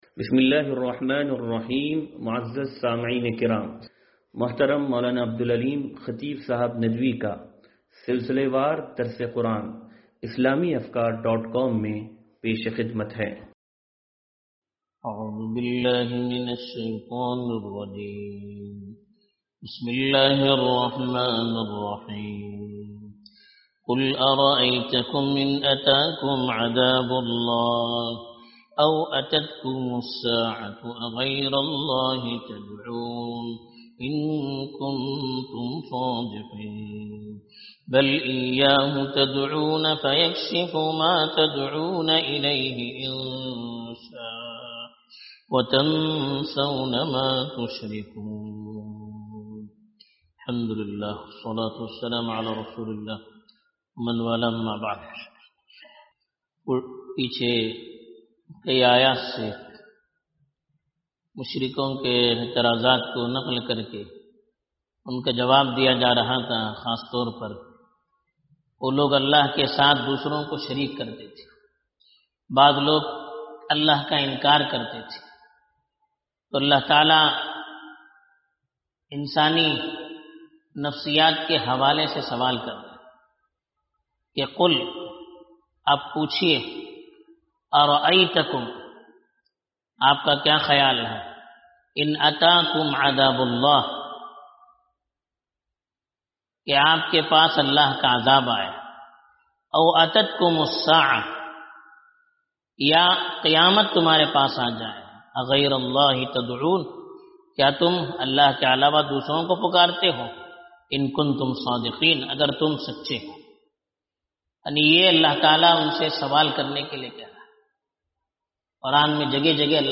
درس قرآن نمبر 0520